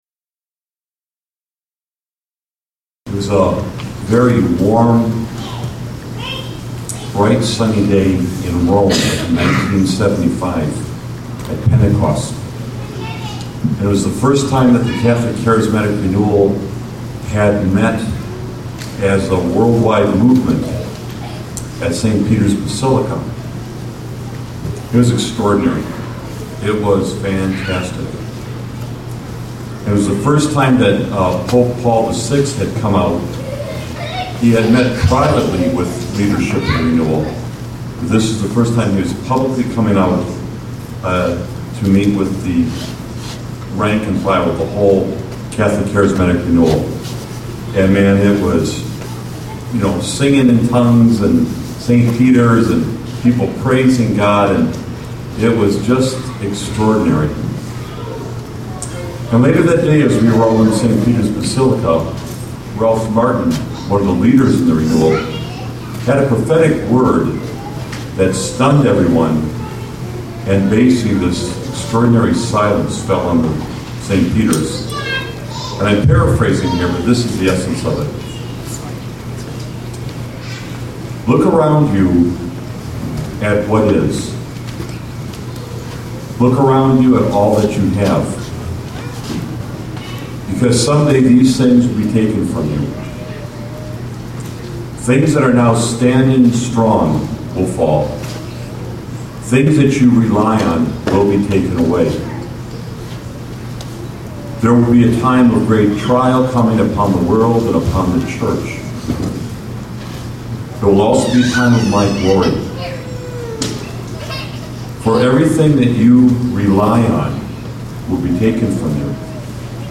Back in June 2009 we celebrated our 25th anniversary in Radway.